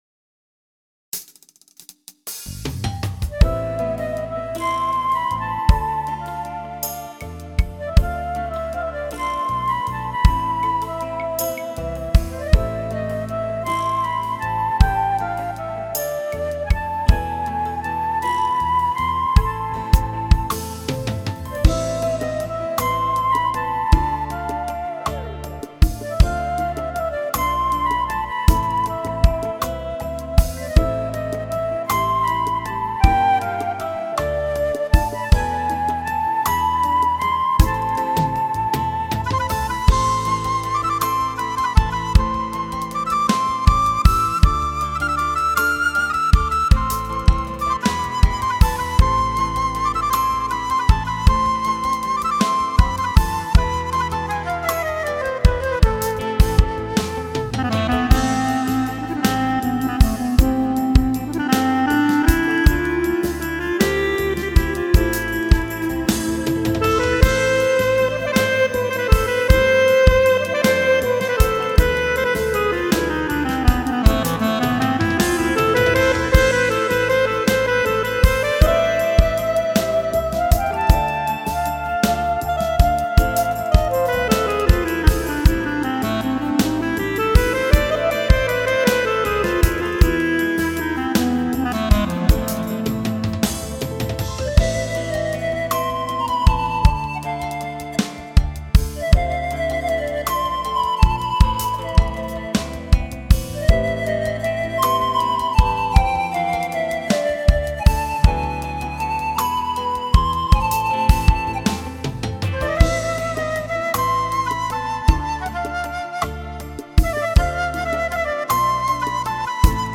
לא מדובר פה באילתורים שלמים לפי האקורד, אלא זה יותר הסולו הרגיל עם תוספות.